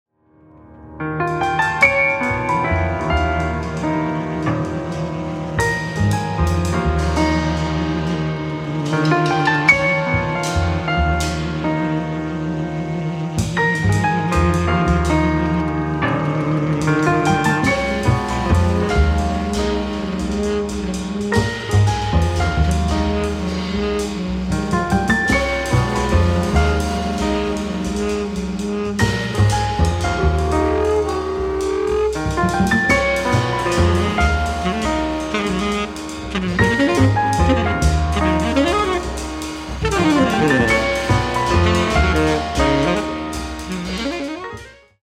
tenor sax
piano, synthesizer
bass
drums